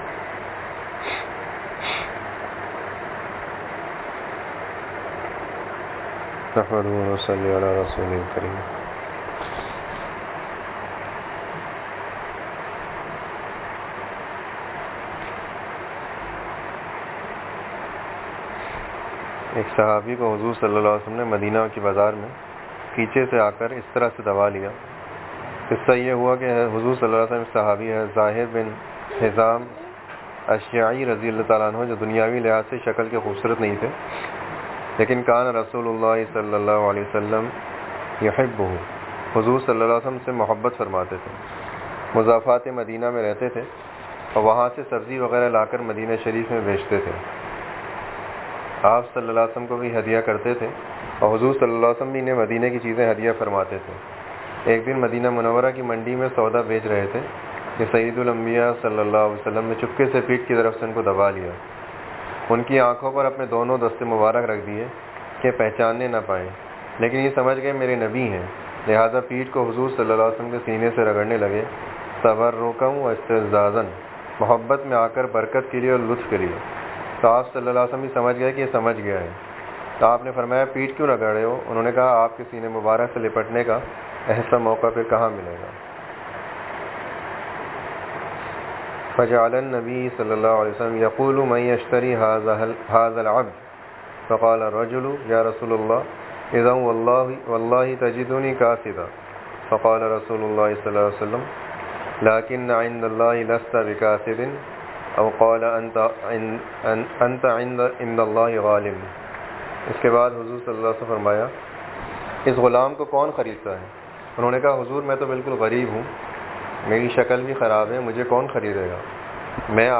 1.2.26( Itwar Majlis ) Huzoor SalAllaho alaihiwasallam ki muhbbat, Chooton sy dua karwana,Tooba Pakki karna,Jamat main Susti Sy AAQA naraaz ,Gaali Daina Gunah Kabeera